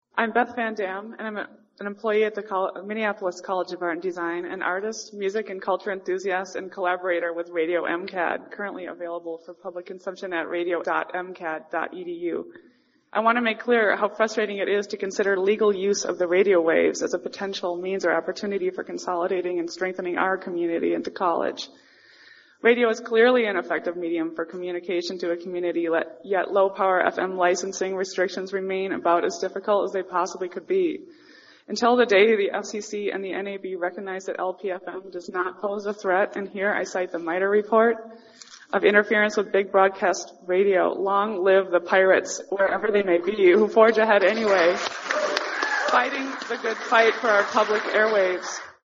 Hundreds of people showed up to the campus of Hamline University, and many gave public testimony (more than 3.5 hours worth).